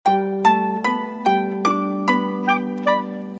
sms 1